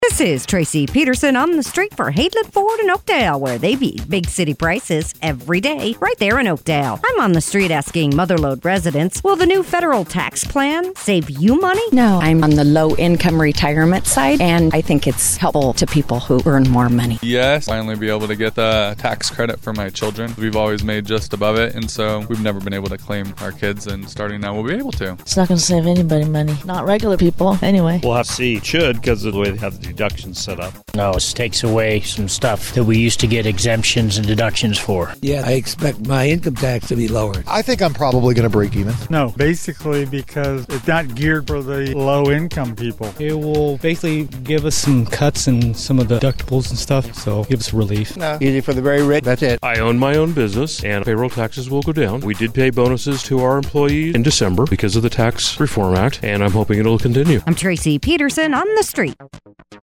asks Mother Lode residents, “Will the new federal tax plan save you money?”